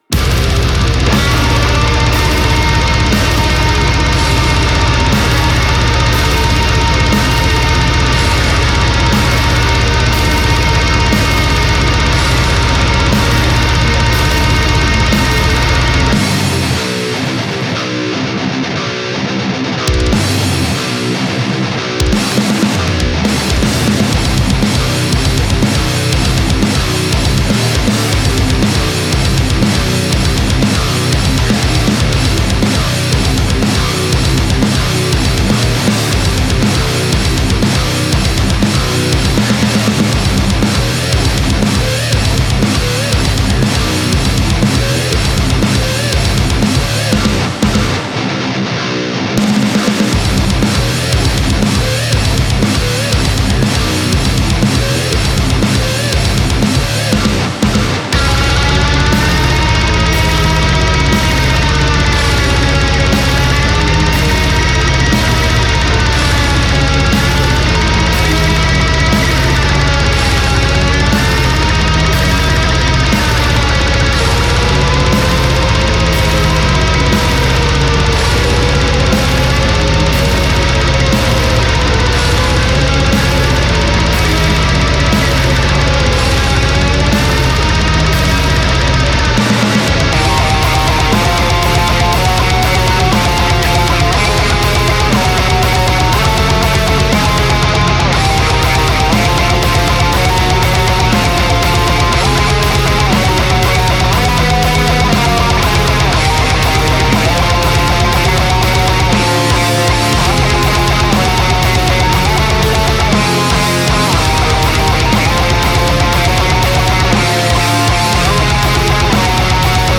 ダークなヘヴィメタルです🔥ラ